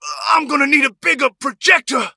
buster_die_vo_05.ogg